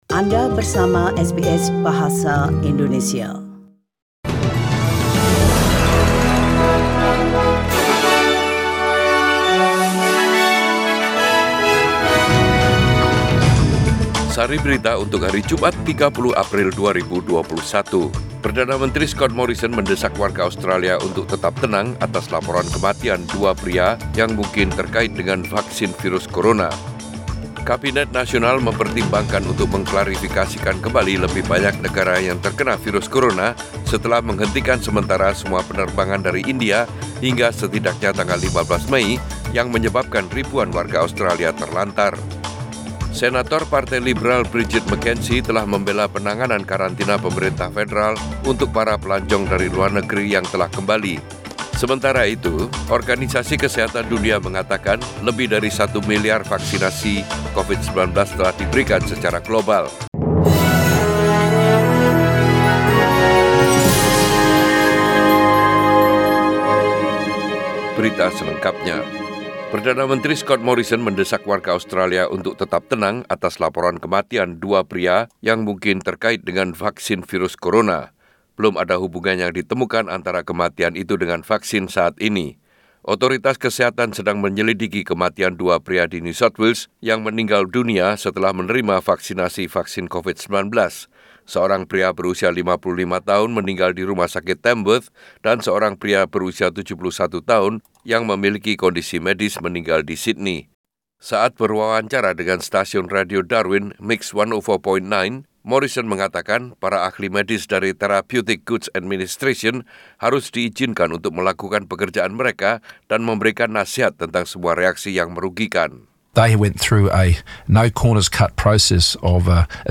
SBS Radio News in Bahasa Indonesia - 30 April 2021